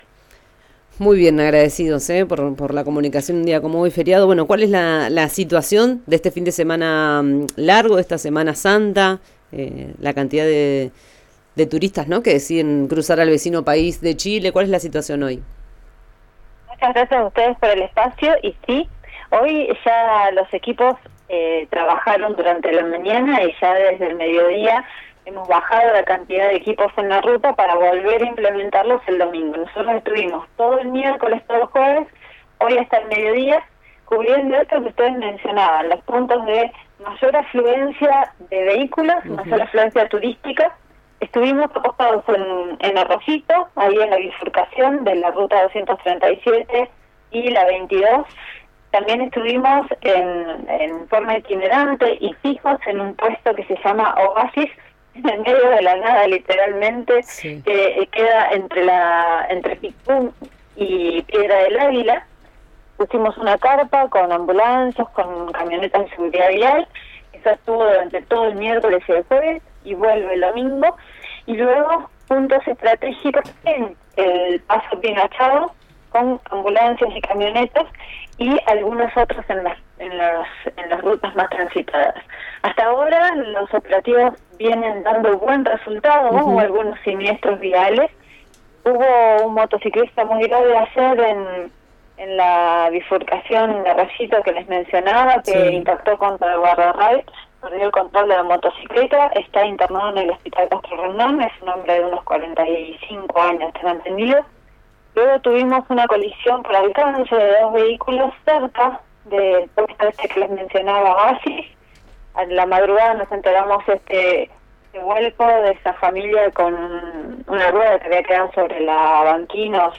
Escuchá a la secretaria de Emergencia y Gestión del Riesgo, Luciana Ortiz Luna, en RÍO NEGRO RADIO: